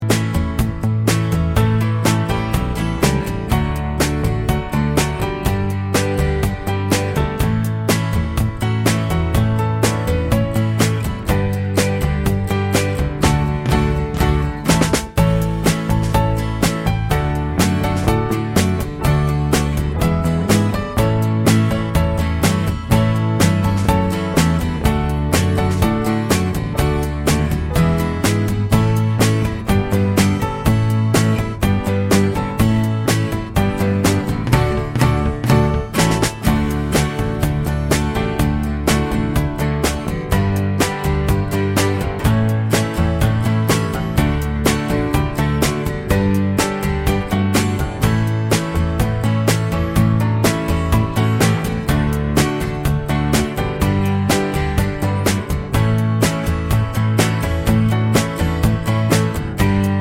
no 2pt harmony Pop (1970s) 2:55 Buy £1.50